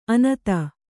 ♪ anata